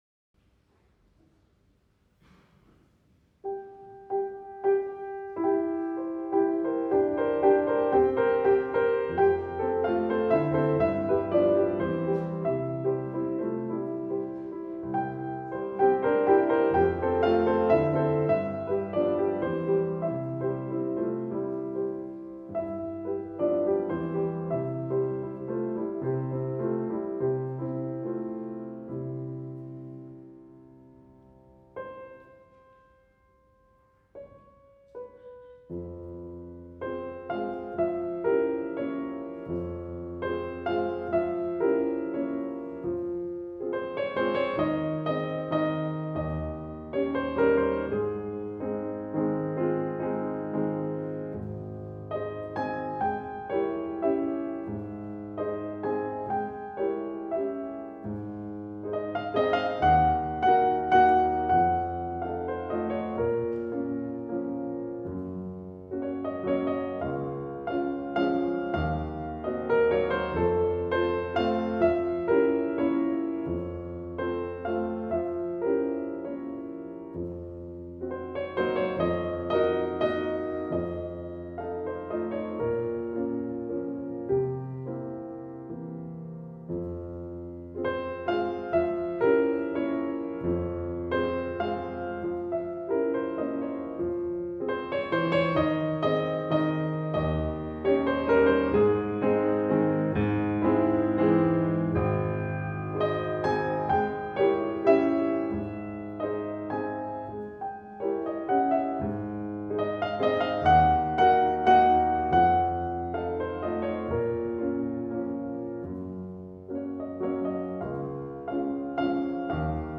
Piano
Performance